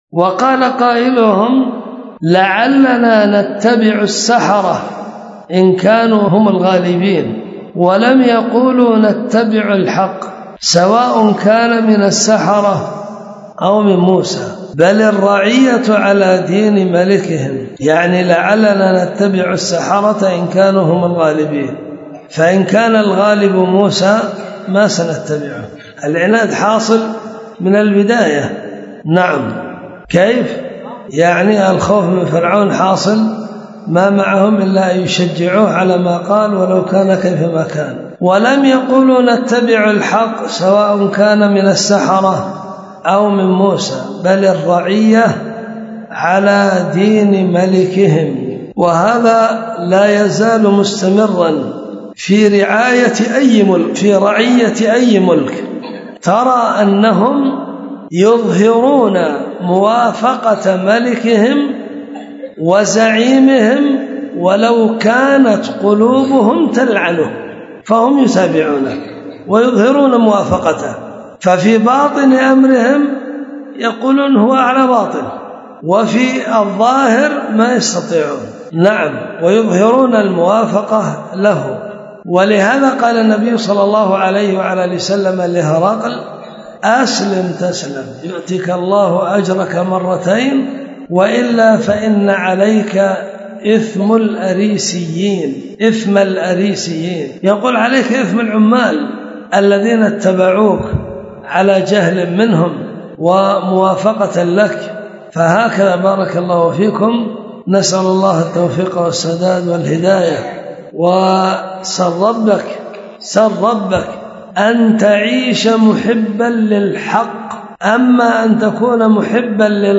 تم قص المقطع من درس تفسير ابن كثير